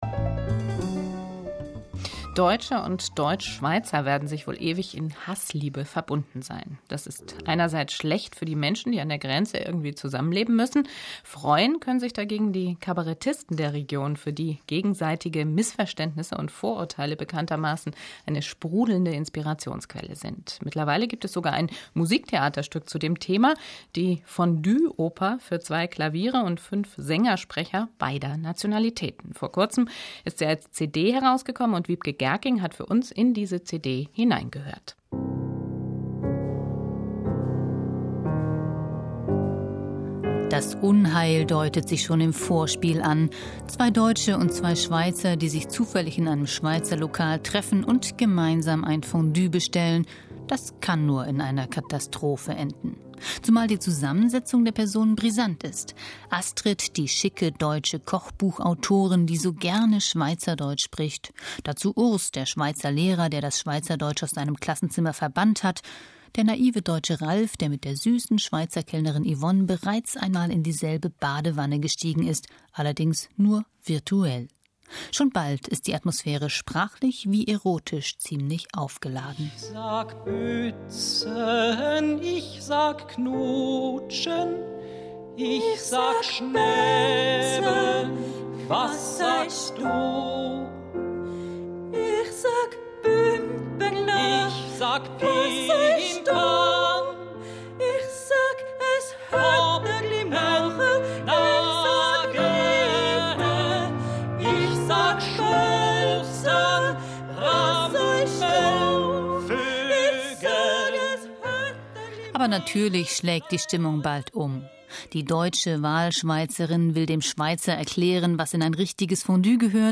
Nur diesmal wird sie gesungen.
Das Hörspiel